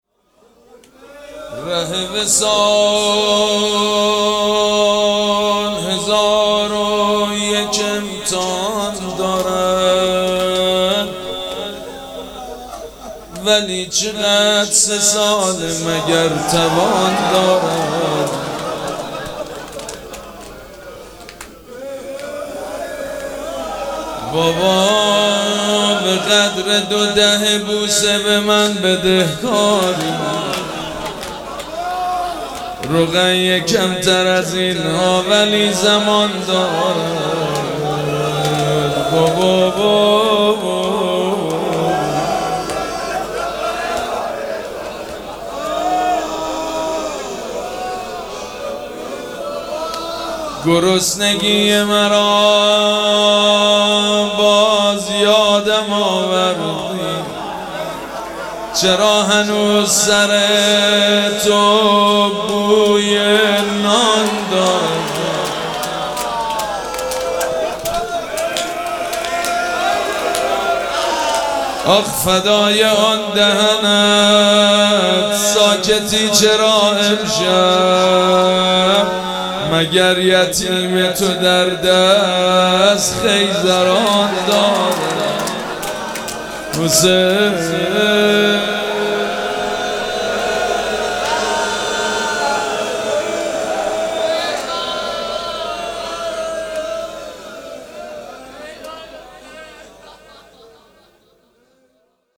مراسم عزاداری شام شهادت حضرت رقیه سلام الله علیها
روضه